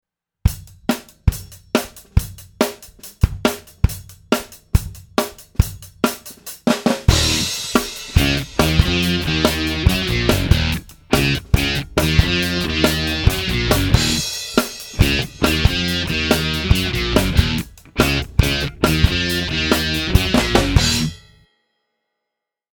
the main riff